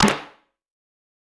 TC3Snare3.wav